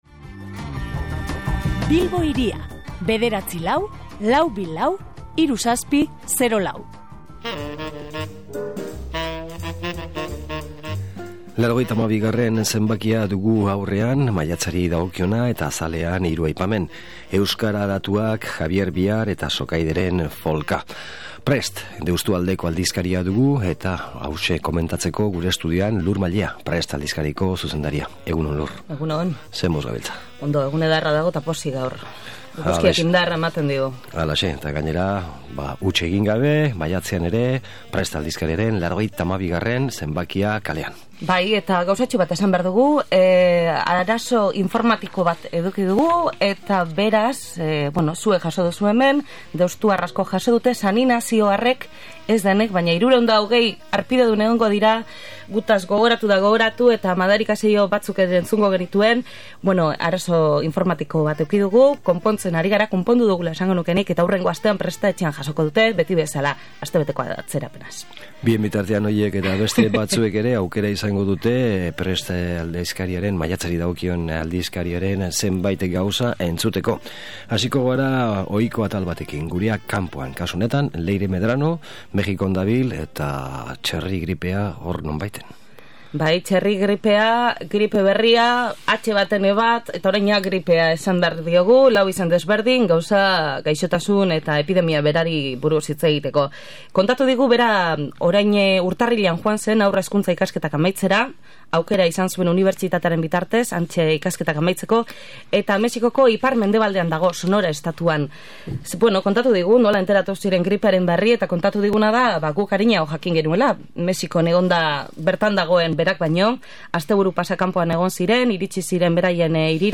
SOLASALDIA: Prest aldizkaria (92.a)